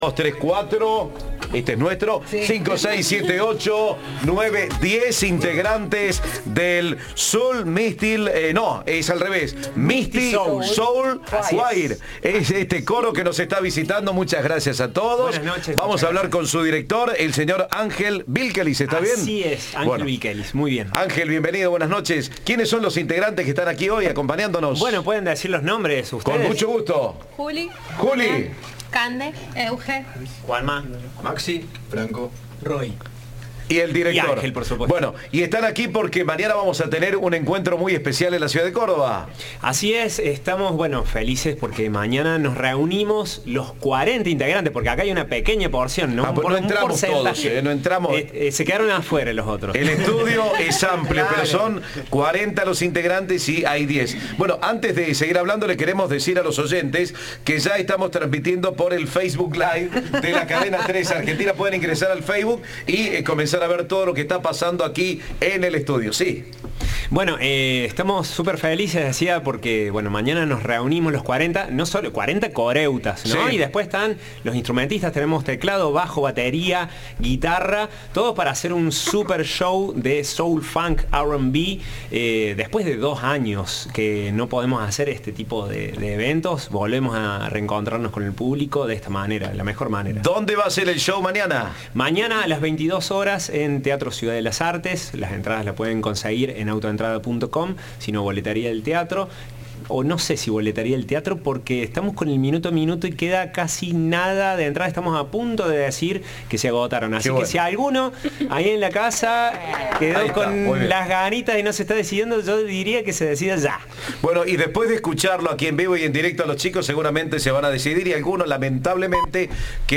Misty Soul Choir: el coro de soul más grande de Argentina
El grupo cordobés que combina solistas, raperos y beat boxers se presenta este sábado en la Ciudad de las Artes. Pero antes, visitaron los estudios de Cadena 3 para deleitar a los oyentes con algunos temas.